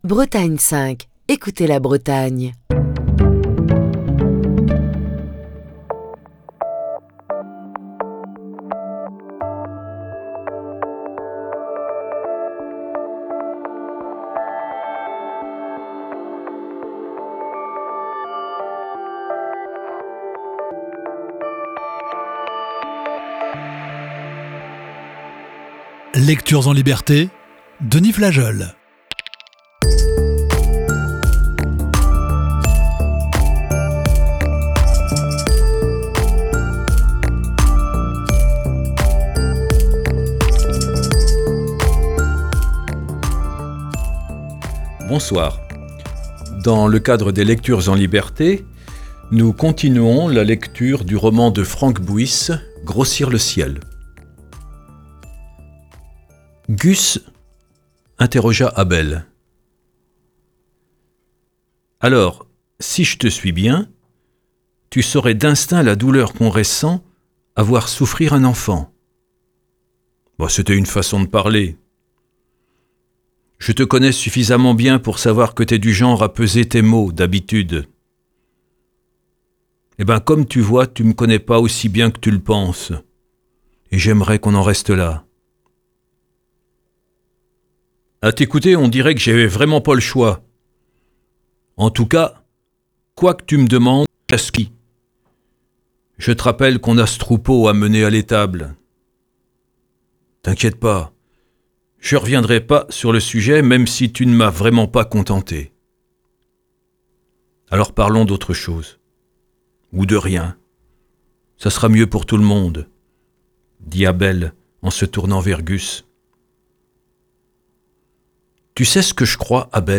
Émission du 28 septembre 2023.